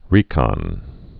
(rēkŏn)